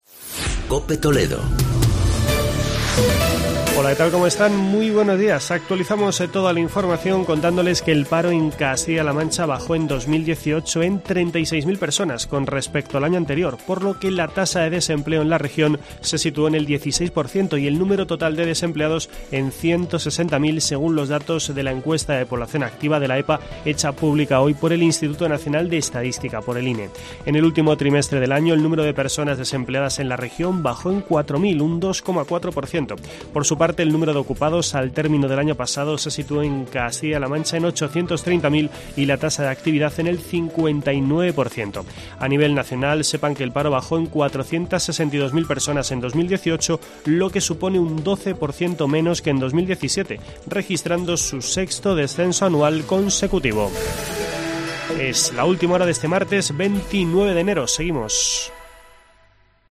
Boletín informativo de COPE Toledo de las 11:00 horas de este martes, 29 de enero de 2019.